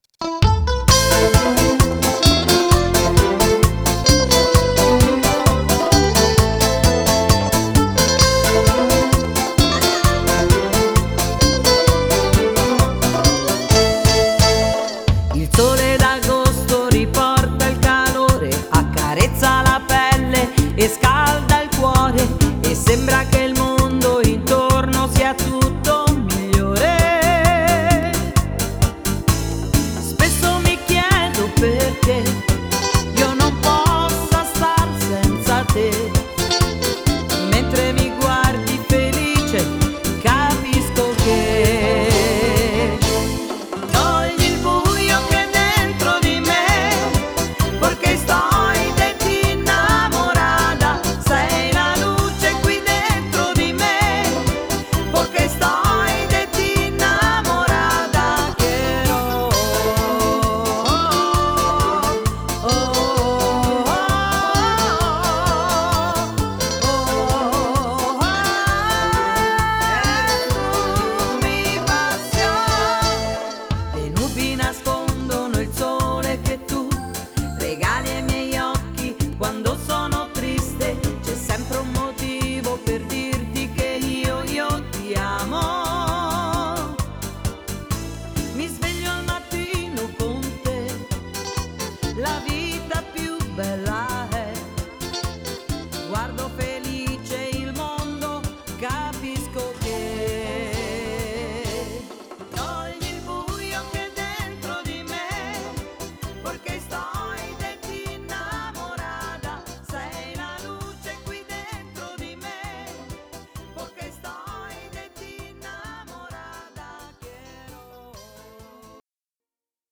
Bachata
12 brani ballabili, 10 inediti e due cover: